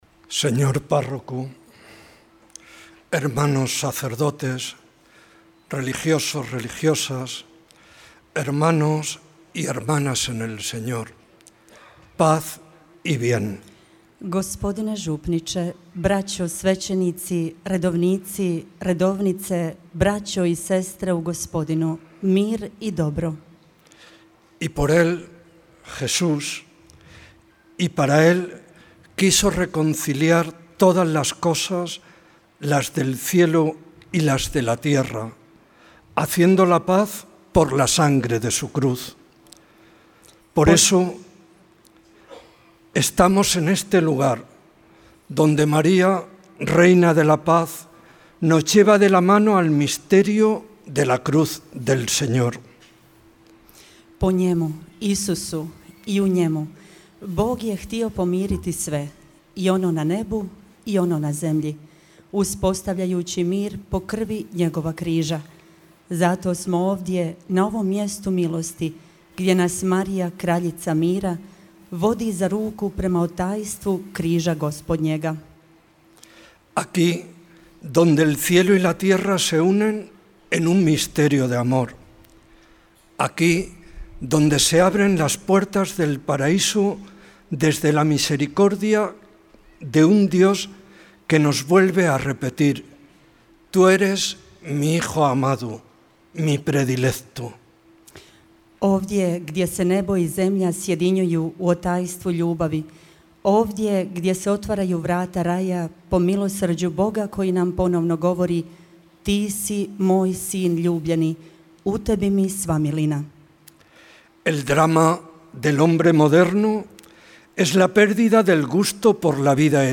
Biskup biskupije Zamora iz Španjolske mons. Fernando Valera Sanchez večeras, 5. rujna 2025., predslavio je euharistijsko slavlje na vanjskom oltaru crkve sv. Jakova u Međugorju.